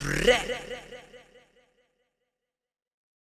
SouthSide Chant (64)(1).wav